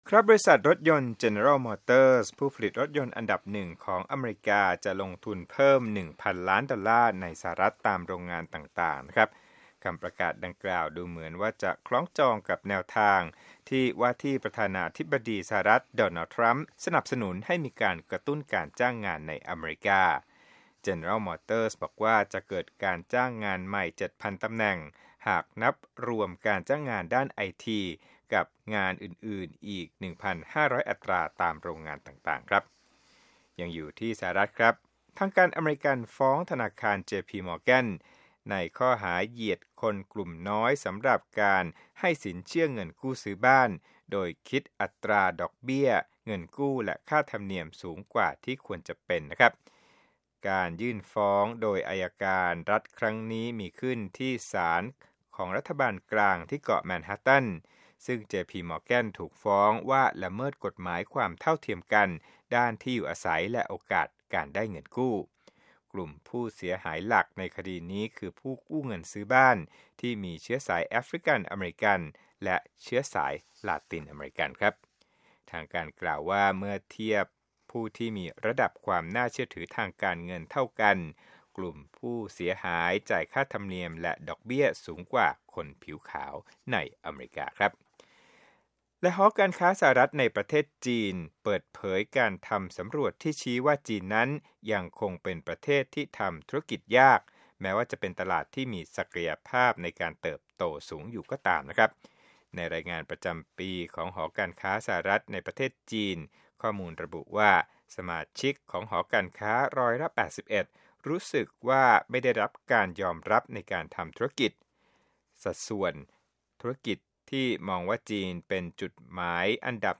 Business News